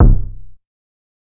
TC3Kick14.wav